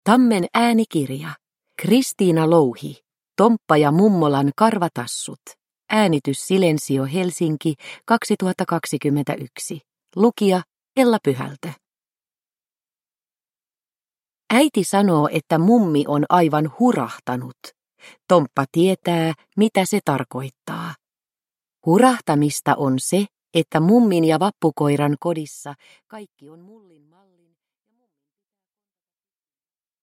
Tomppa ja mummolan karvatassut – Ljudbok – Laddas ner